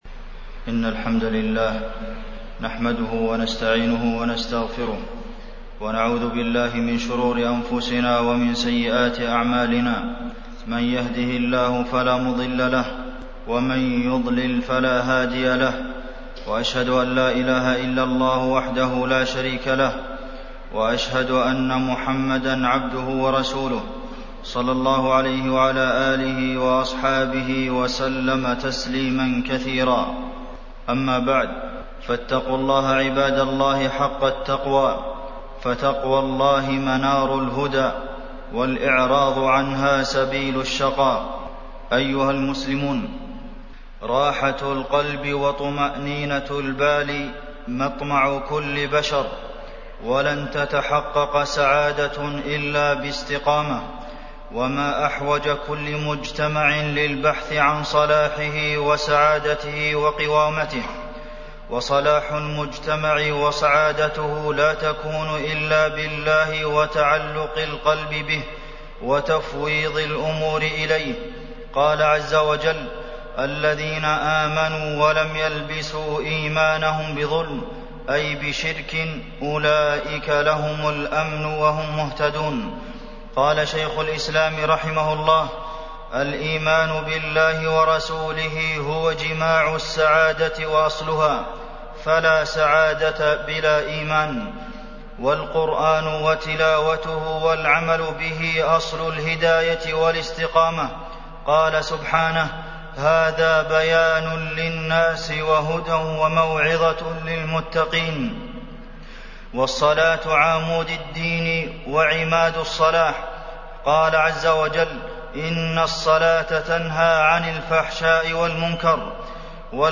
تاريخ النشر ١٤ جمادى الآخرة ١٤٢٨ هـ المكان: المسجد النبوي الشيخ: فضيلة الشيخ د. عبدالمحسن بن محمد القاسم فضيلة الشيخ د. عبدالمحسن بن محمد القاسم طريق الفلاح